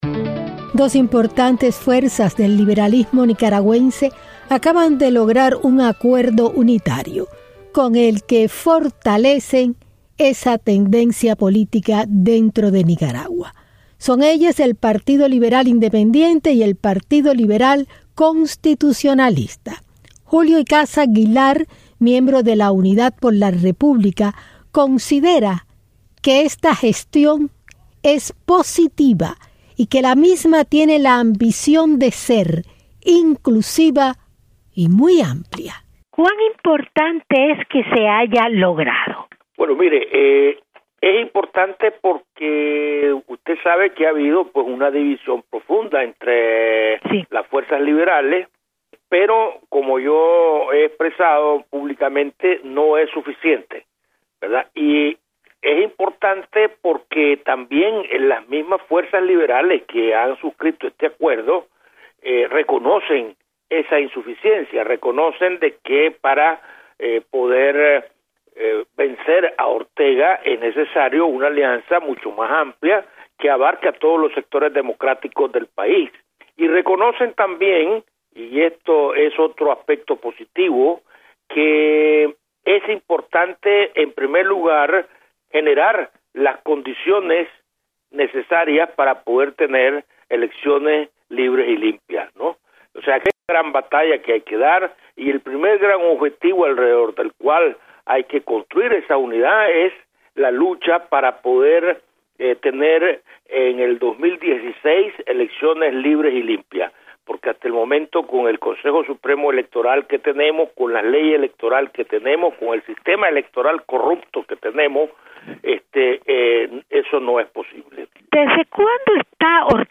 Entrev en España./